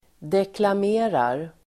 Uttal: [deklam'e:rar]
deklamerar.mp3